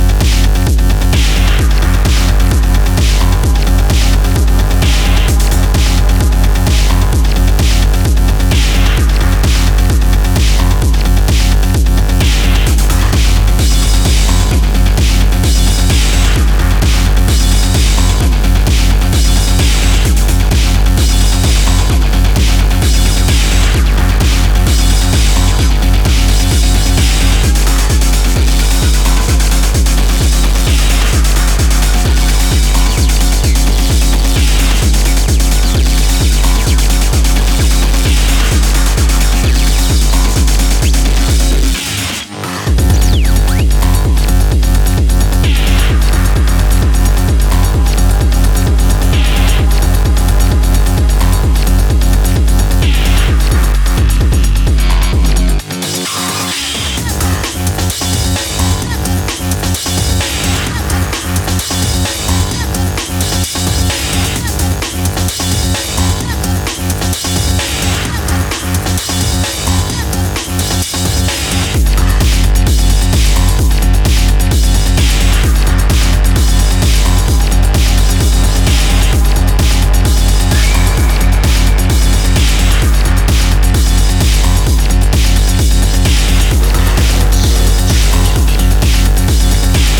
Electronic music
Techno